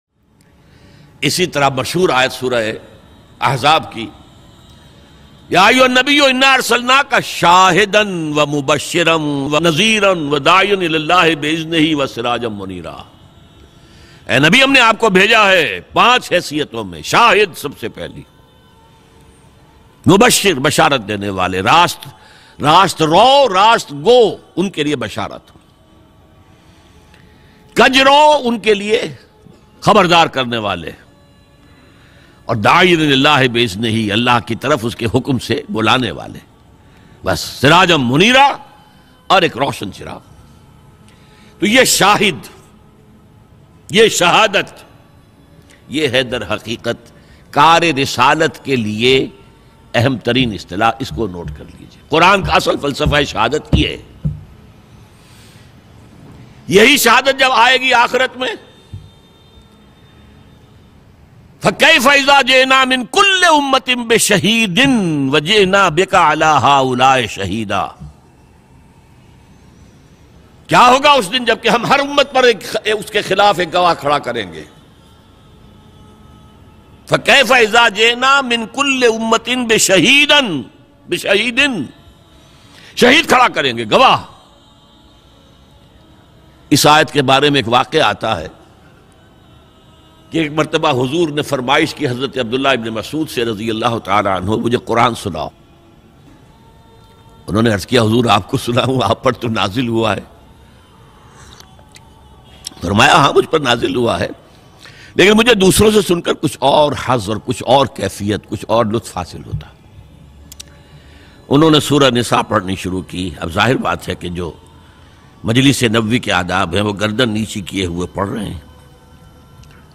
Huzoor Ki Ankhon Mein Ansoo Bayan MP3 Download Dr Israr Ahmed
Dr Israr Ahmed R.A a renowned Islamic scholar.